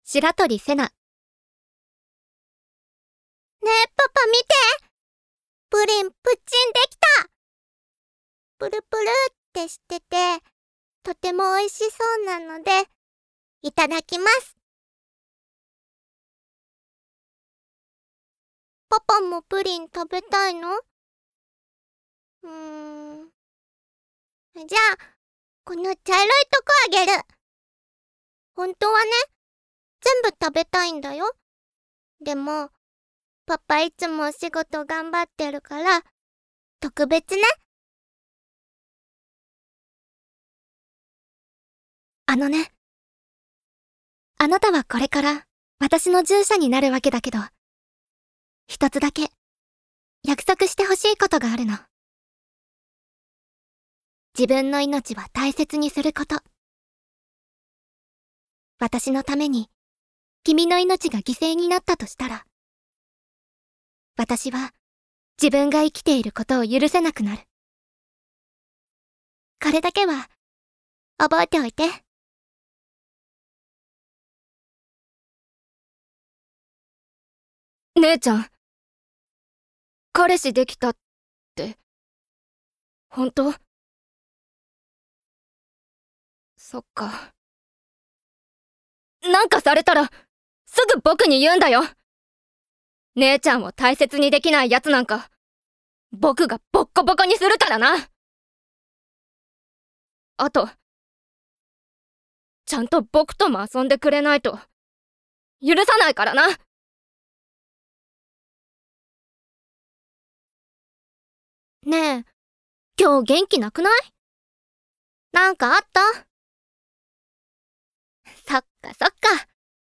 誕生日： 6月21日 血液型： A型 身 長： 155cm 出身地： 千葉県 趣味・特技： 読書、中国ドラマ、乙女ゲーム、岩盤浴、バレーボール(3年)、ピアノ(9年) 資格： TOEIC(650点)、自動車運転免許、日本漢字能力検定2級” 音域： A3－C5
VOICE SAMPLE